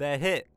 Voice Lines / Barklines Combat VA